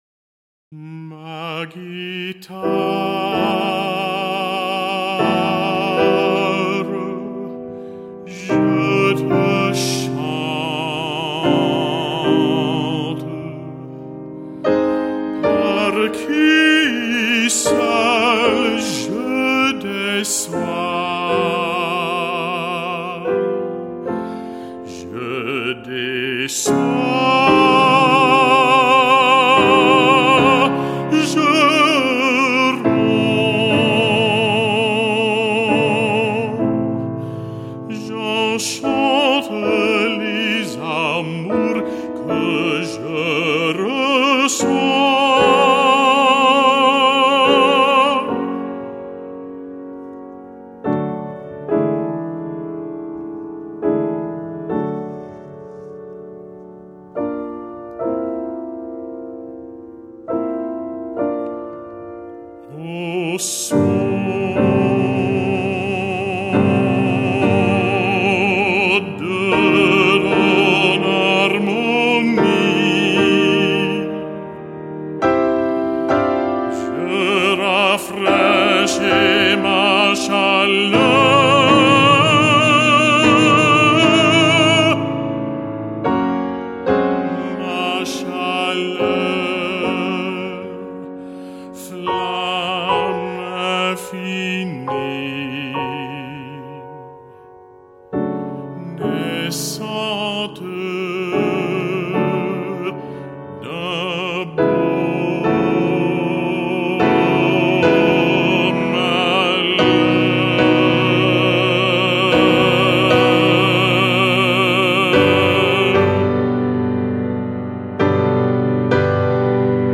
Baritone or Mezzo-Soprano & Piano (11′)